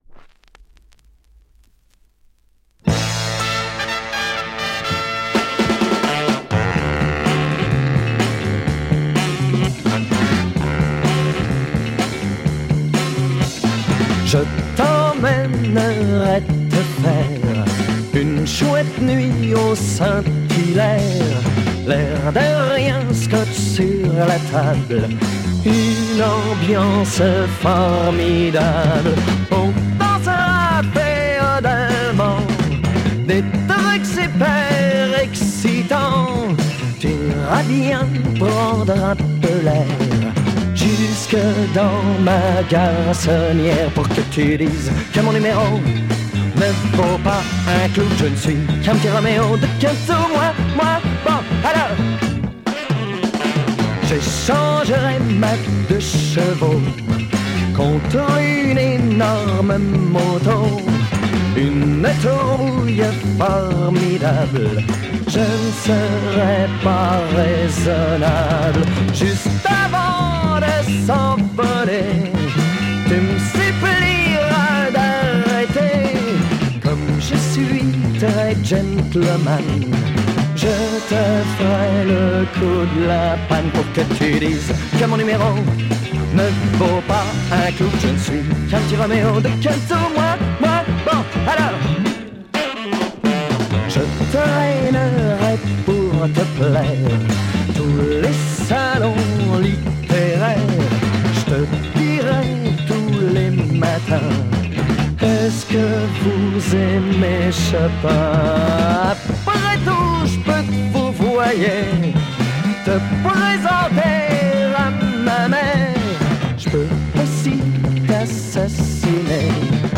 Killer French Bespoke Soul-sike dancer 7" NM!